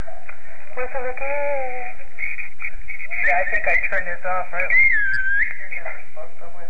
Some E.V.P. captured at St. Mary's Hospital:
EVP_Whistle_StM.wav